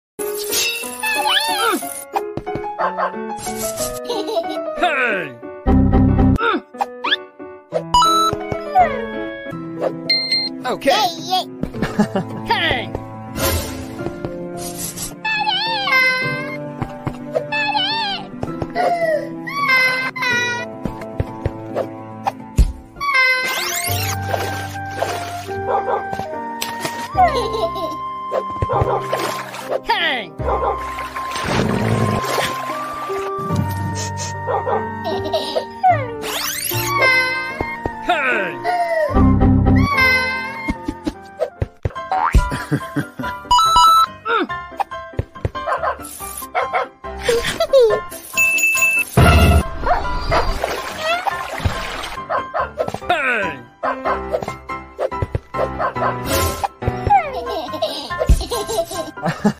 MONSTER SCHOOL_ Herobrine Baby Crying sound effects free download